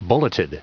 Prononciation du mot bulleted en anglais (fichier audio)
Prononciation du mot : bulleted